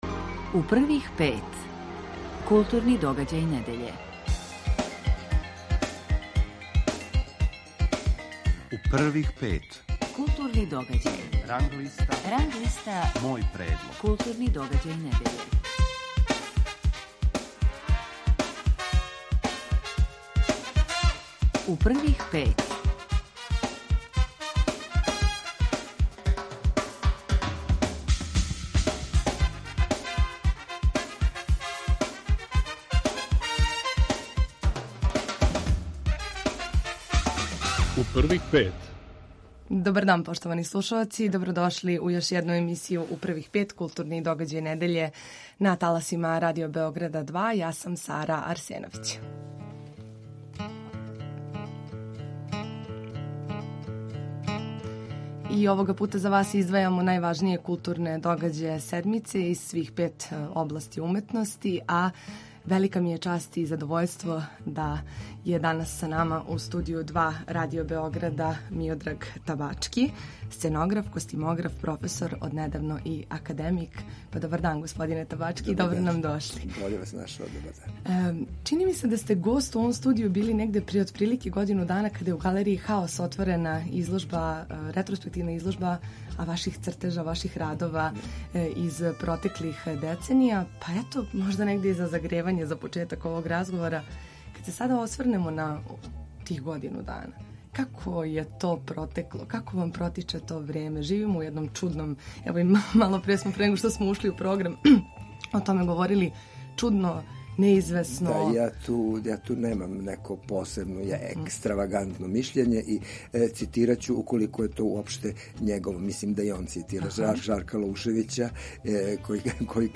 Гост емисије је Миодраг Табачки.